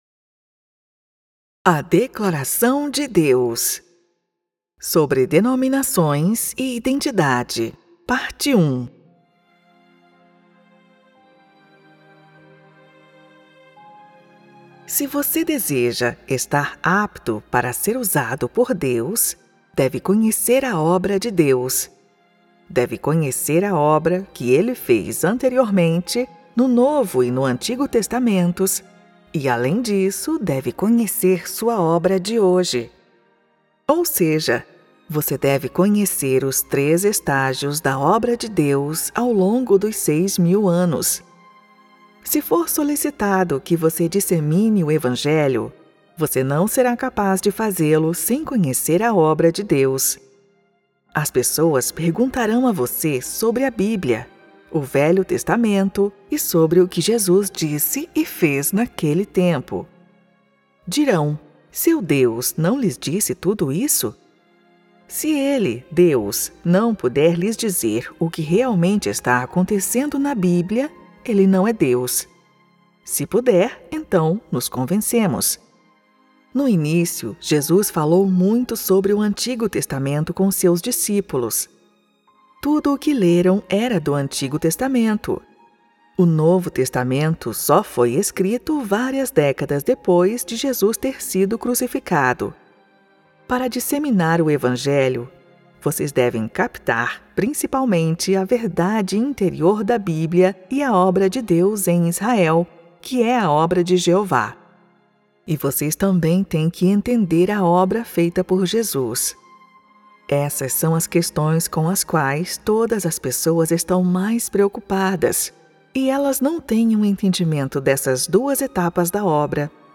Categories: Readings